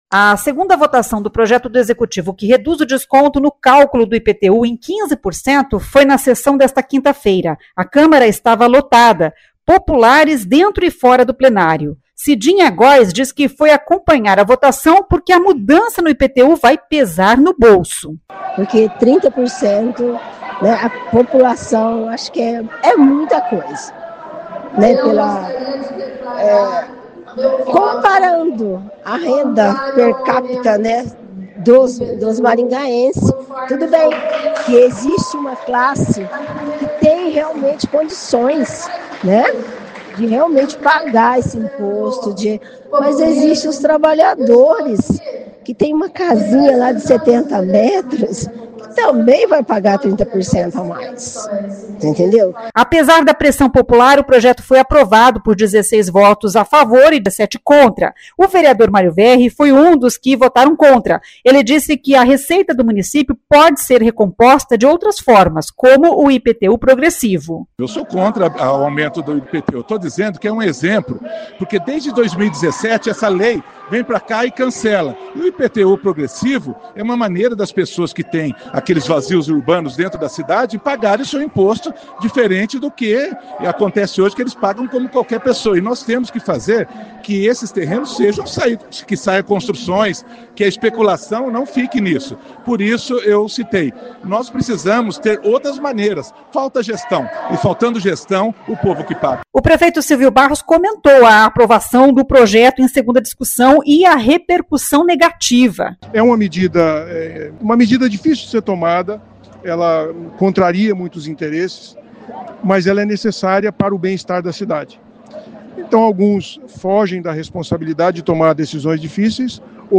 Legislativo
O vereador Mário Verri foi um dos que votaram contra.
O prefeito Silvio Barros comentou a aprovação do projeto em segunda discussão e a repercussão negativa.[ouça o áudio]